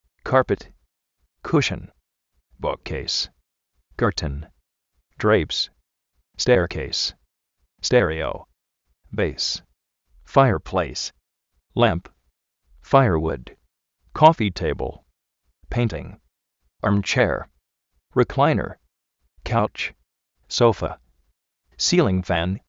Vocabulario en ingles, diccionarios de ingles sonoros, con sonido, parlantes, curso de ingles gratis
kárpet
kúshon
buk-kéis